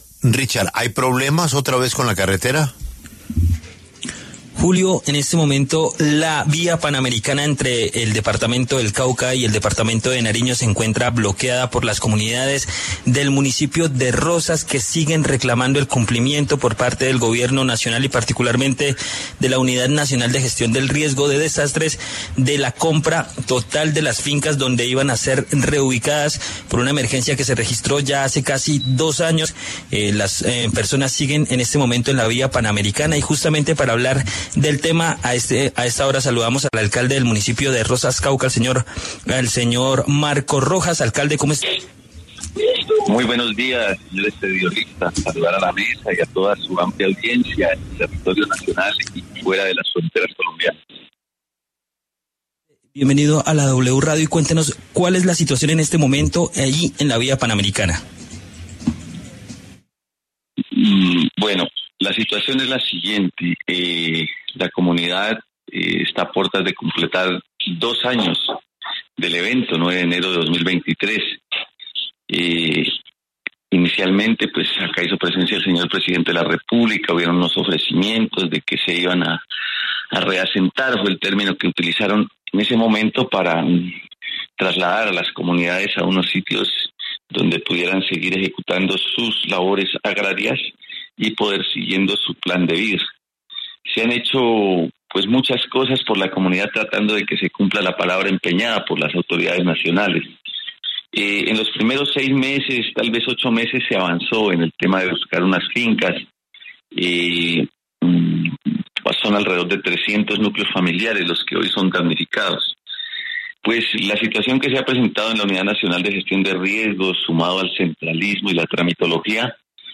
El alcalde de Rosas, Cauca, Marco Rojas, pasó por los micrófonos de La W para referirse a la situación de las comunidades que bloquean la vía Panamericana en el sur de la región.